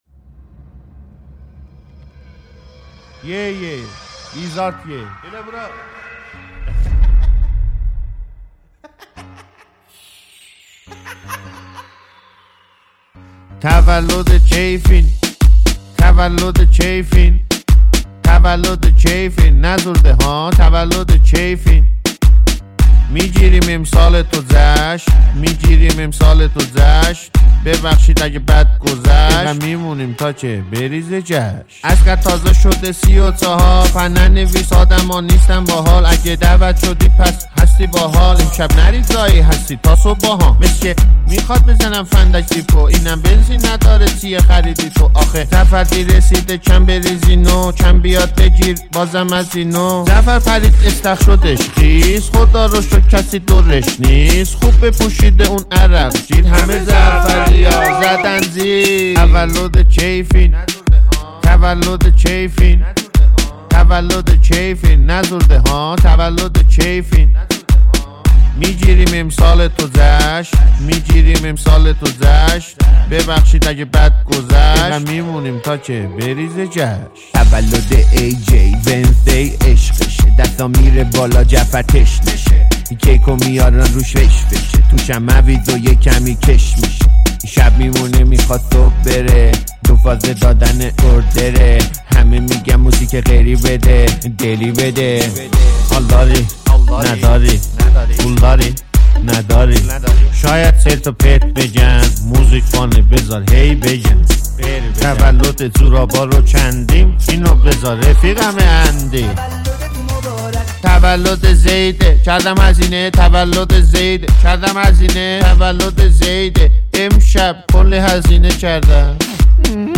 ریمیکس بیس دار
ریمیکس بیس دار تند